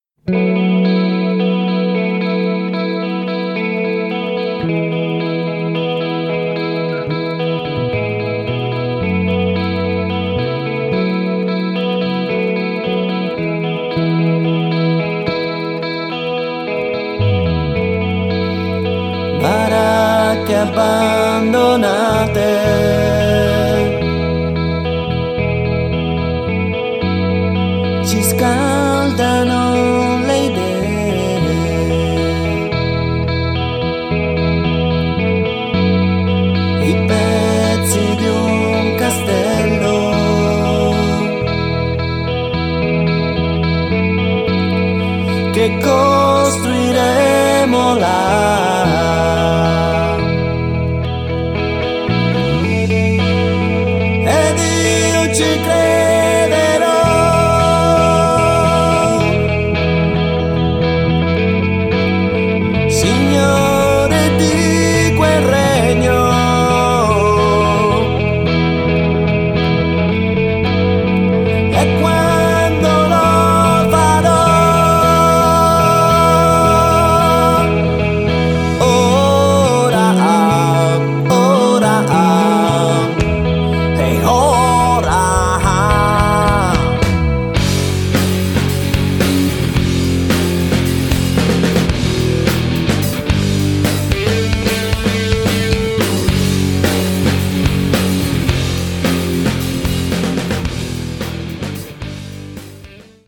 rock music
Genere: Rock.